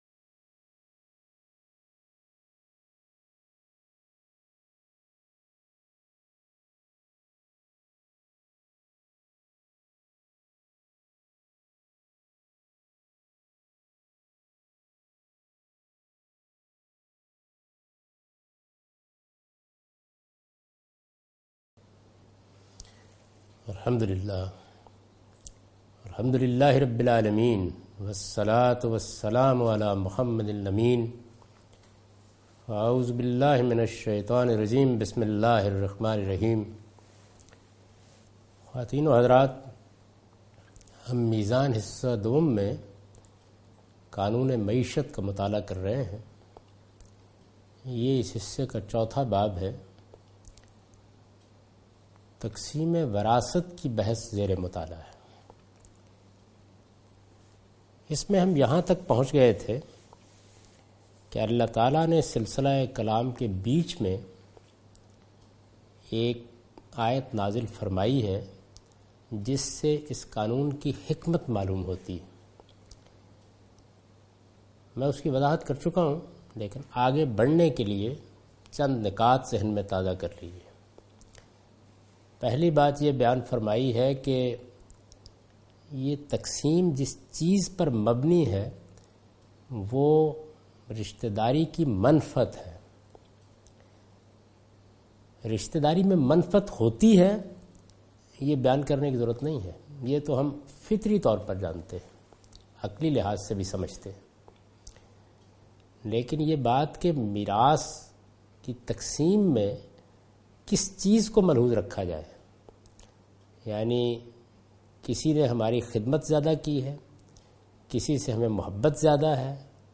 Meezan Lecture 'Meezan Class— "Economic Directive". Javed Ahmad Ghamidi teaching from his book Meezan. In this lecture he teaches from the chapter "Qanoon e Maeshat".